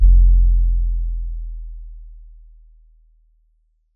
BWB UPGRADE3 OPEN 808 (6).wav